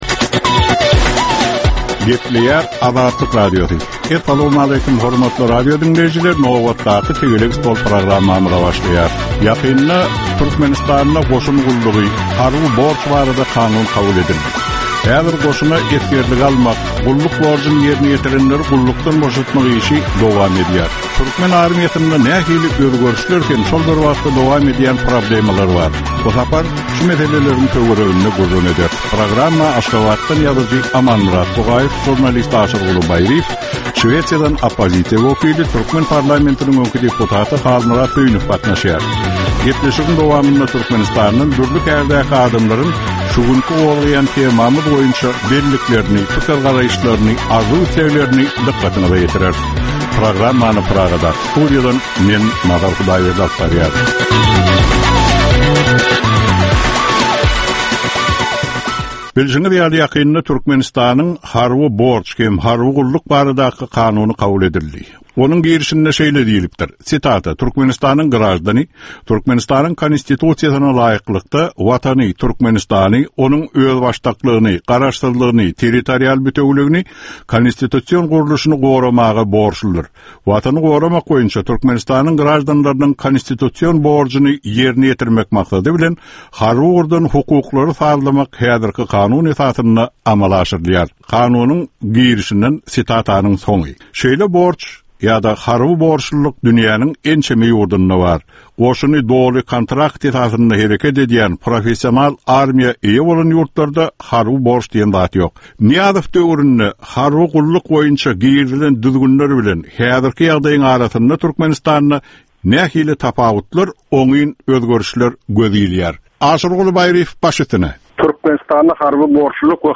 Jemgyýetçilik durmuşynda bolan ýa-da bolup duran soňky möhum wakalara ýa-da problemalara bagyşlanylyp taýýarlanylýan ýörite “Tegelek stol” diskussiýasy. Bu gepleşikde syýasatçylar, analitikler we synçylar anyk meseleler boýunça öz garaýyşlaryny we tekliplerini orta atýarlar.